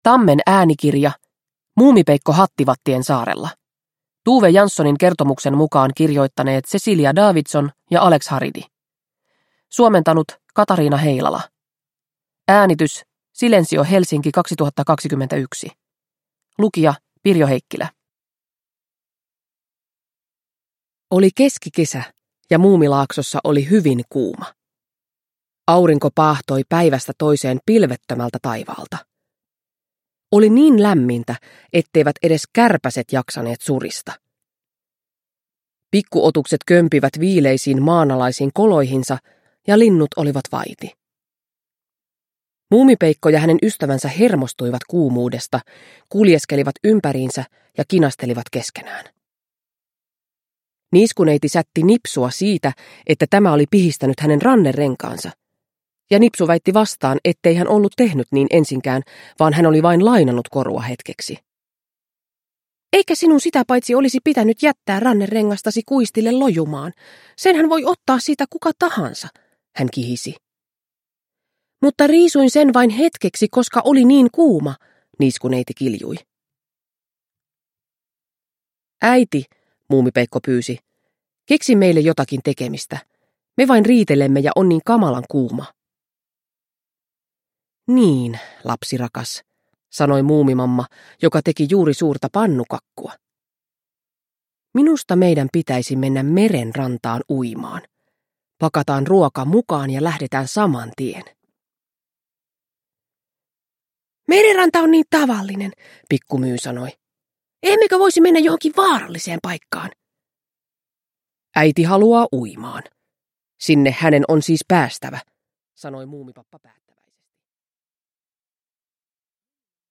Muumipeikko hattivattien saarella – Ljudbok – Laddas ner